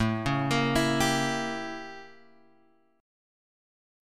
A7b9 chord {5 4 x 3 5 3} chord